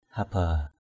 haba.mp3